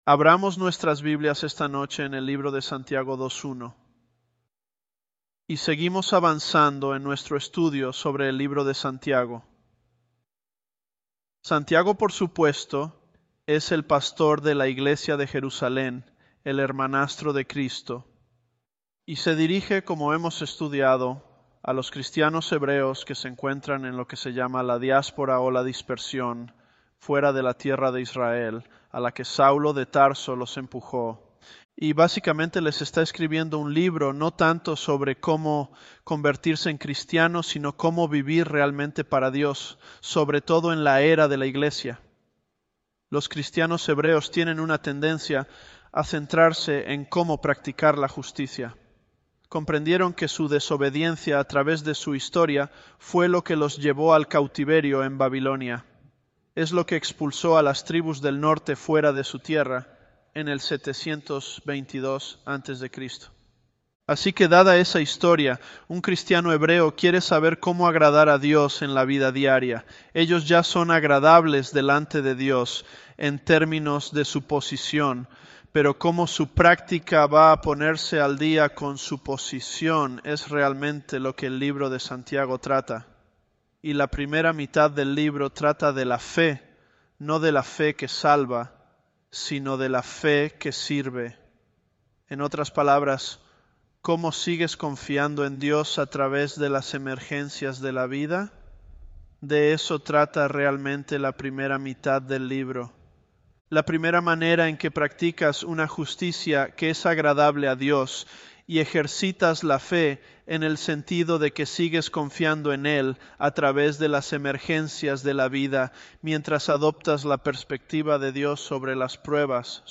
Sermons
Elevenlabs_James008.mp3